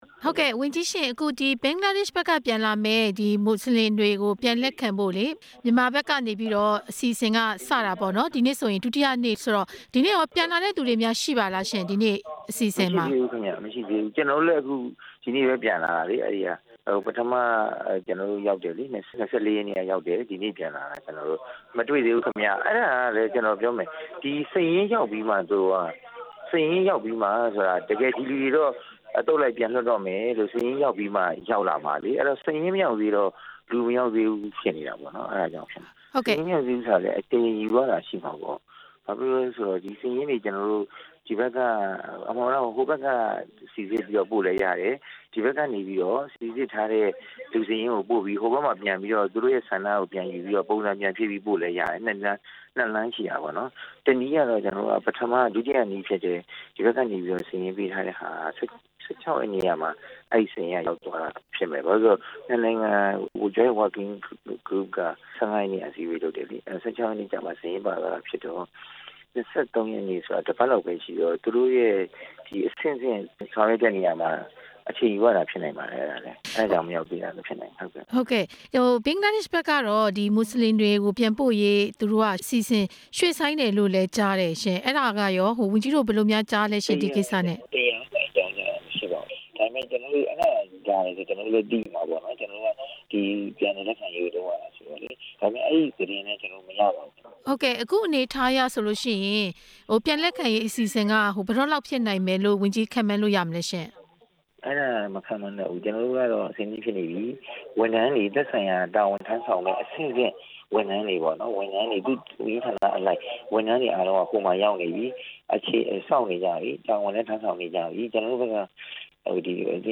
မွတ်စလင်ဒုက္ခသည် လက်ခံရေးနဲ့ပတ်သက်ပြီး မေးမြန်းချက်